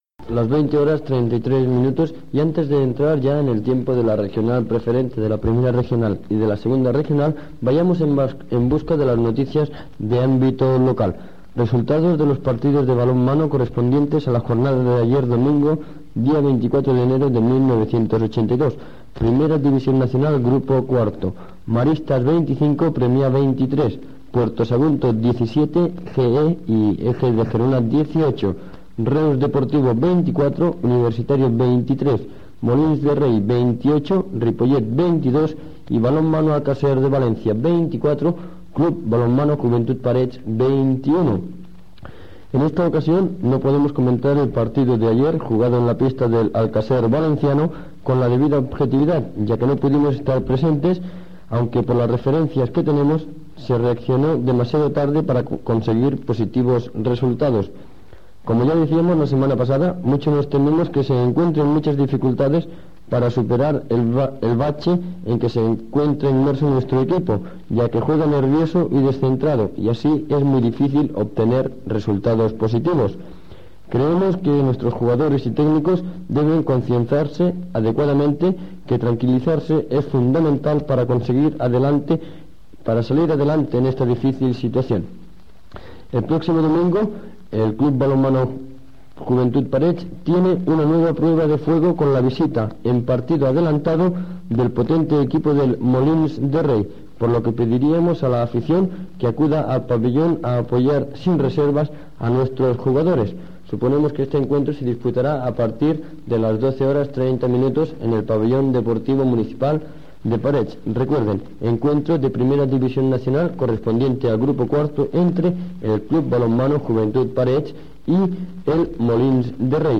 Informatiu esportiu
FM